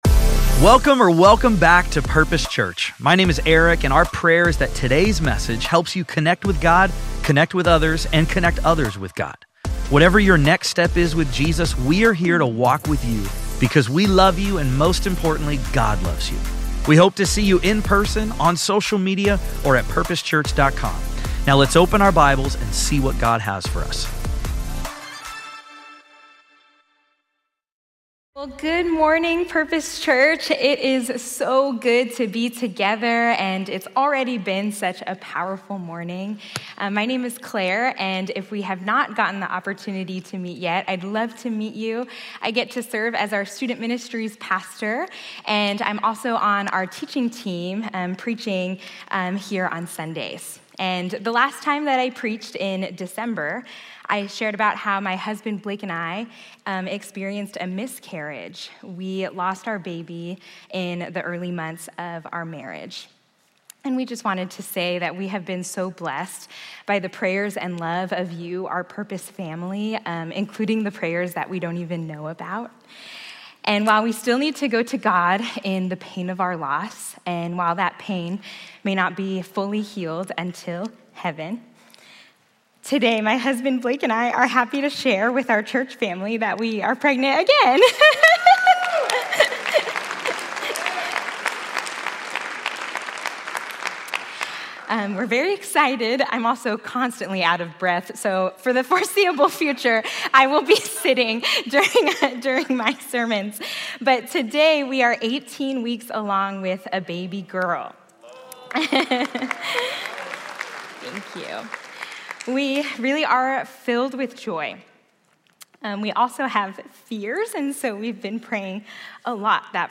In our final message of Seven Decisions Great Leaders Make